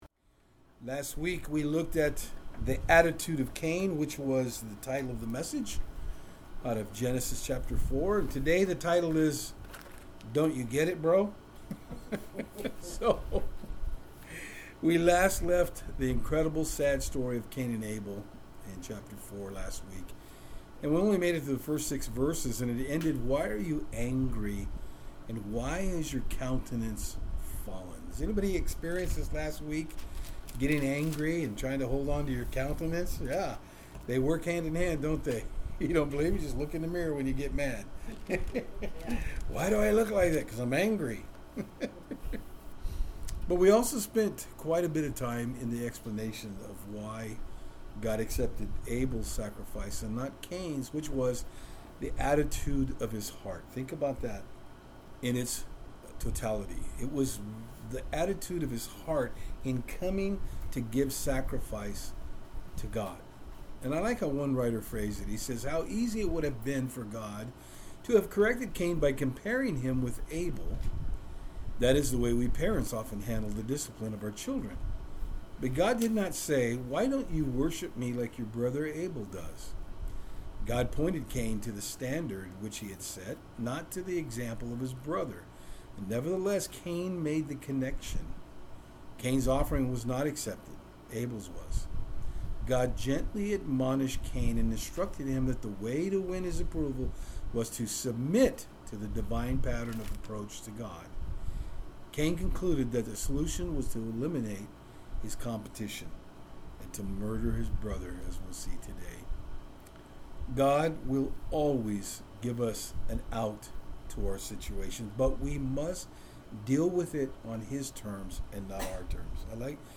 Genesis 4:7-16 Service Type: Saturdays on Fort Hill In our study today we look at Cains incredible self loathing attitude as well as his murderous heart.